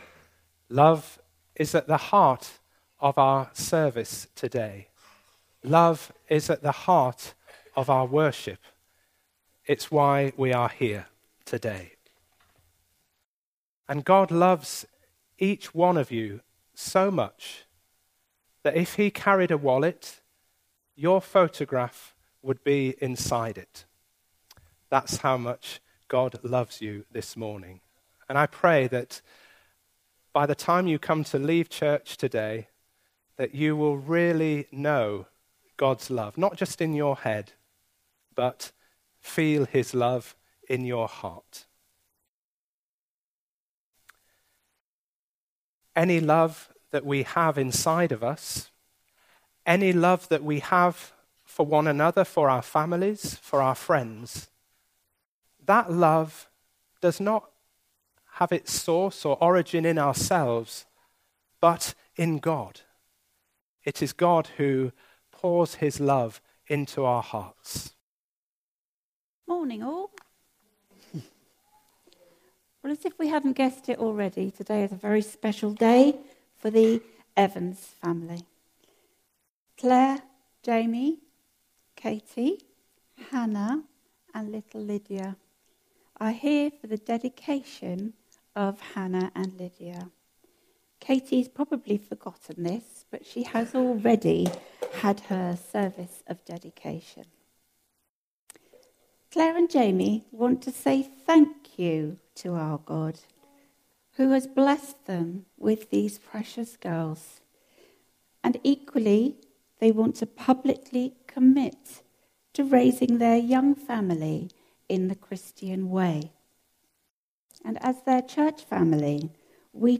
Service Type: All Age Worship